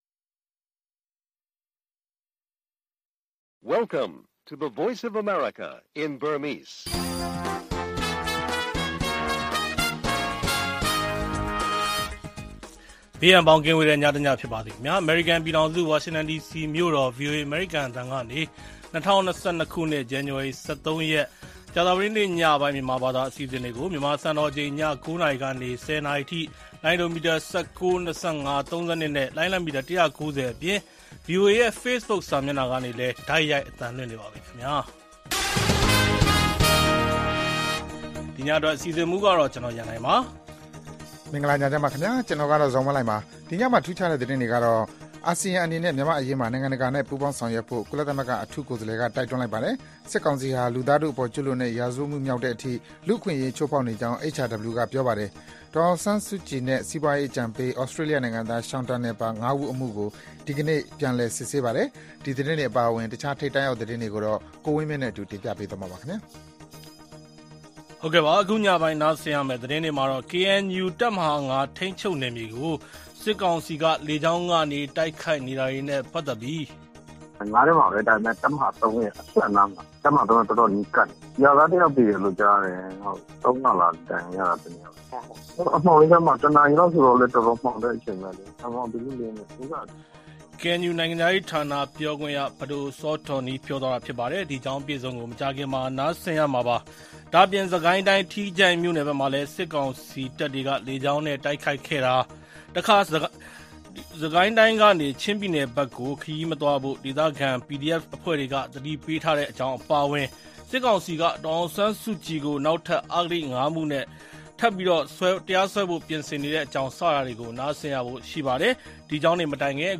ဗွီအိုအေ ကြာသပတေးည ၉း၀၀-၁၀း၀၀ နာရီ ရေဒီယို/ရုပ်သံလွှင့်အစီအစဉ်